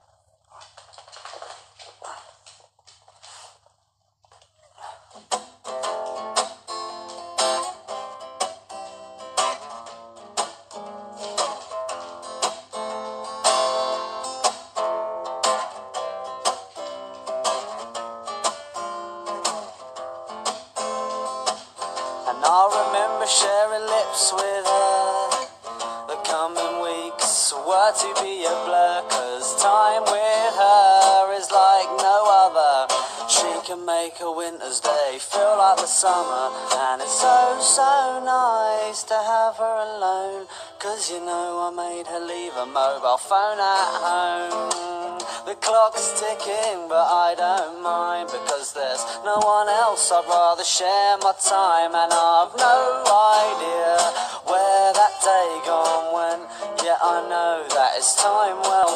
Young Tom Felton singing 🎶 sound effects free download